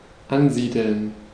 Ääntäminen
US : IPA : [ˈsɛtəl]